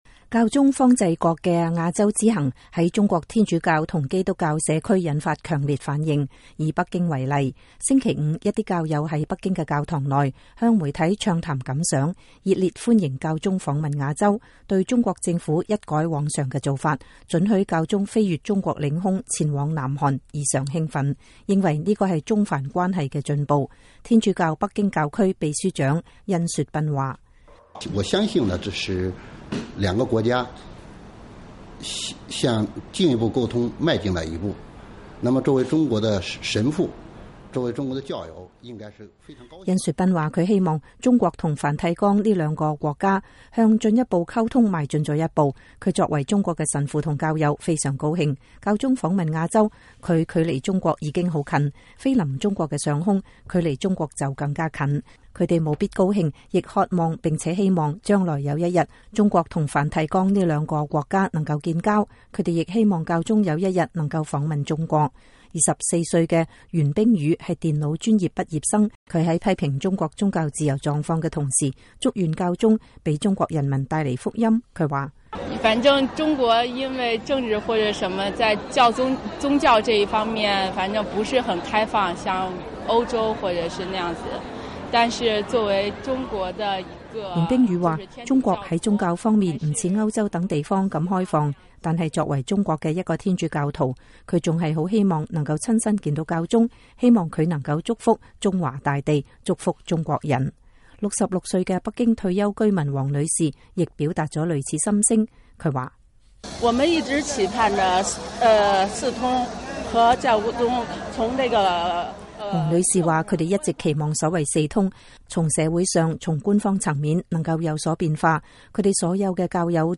2014-08-15 美國之音視頻新聞: 教宗亞洲之行引發中國教徒強烈反響